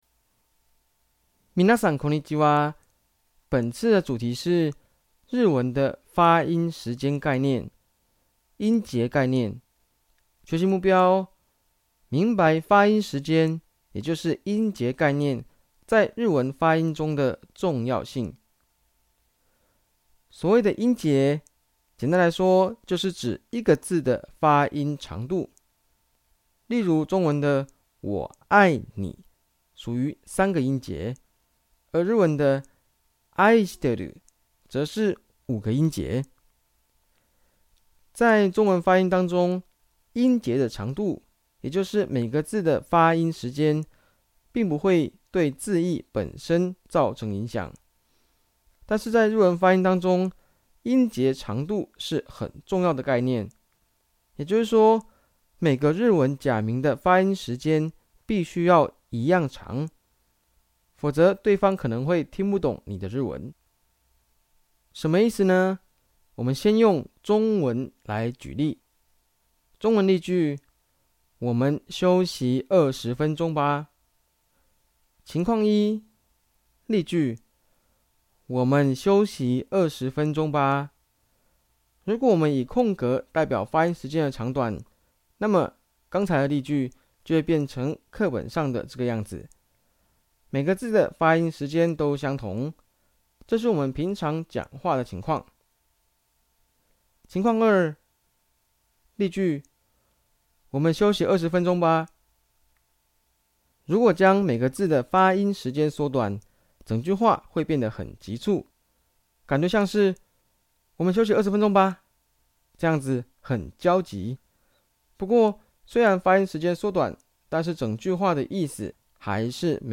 聲音解說：